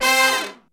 C4 POP FALL.wav